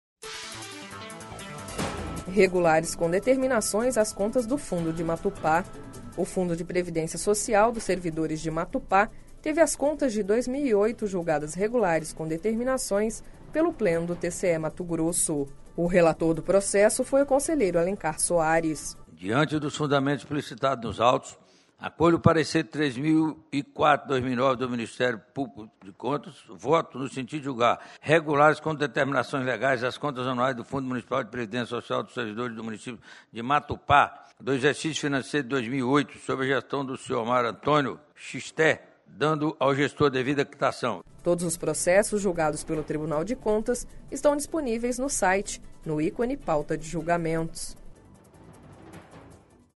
Sonora: Alencar Soares - conselheiro do TCE-MT